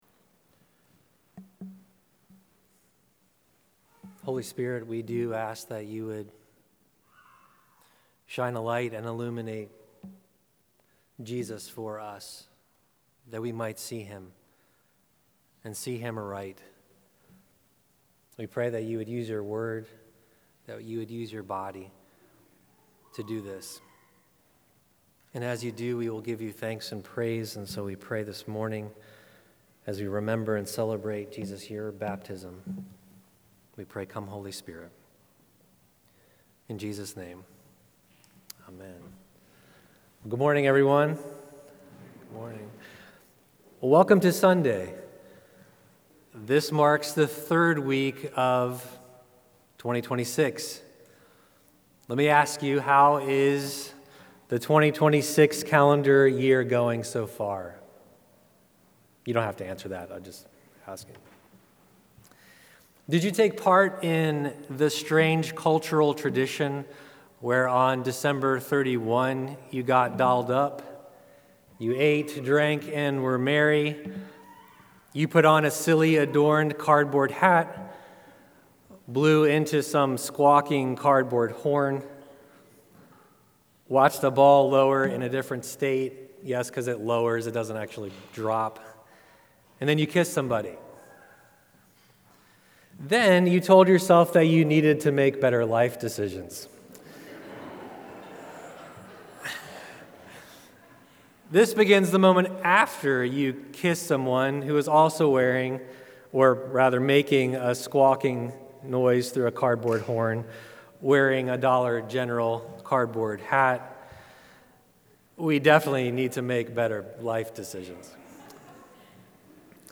Sermons | Church of the Good Shepherd